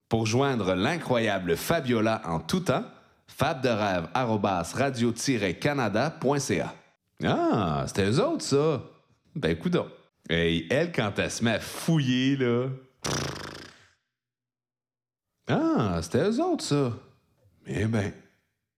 Habillage sonore